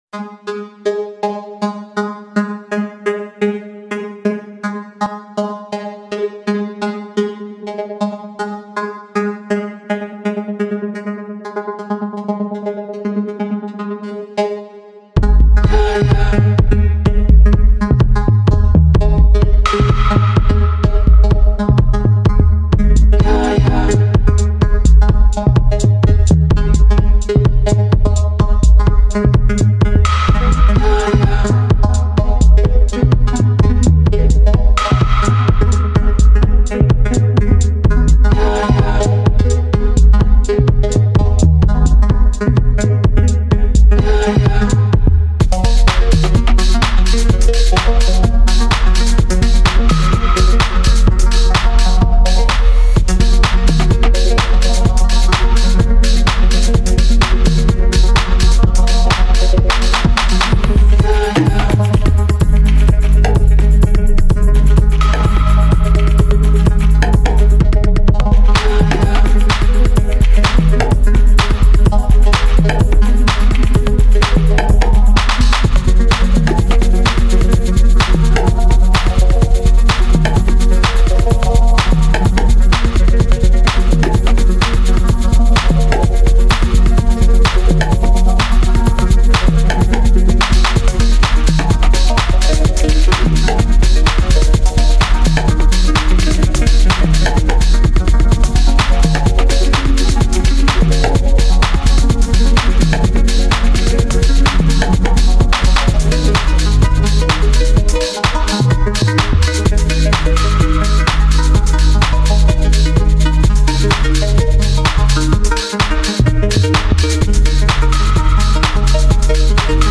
ambient-music.mp3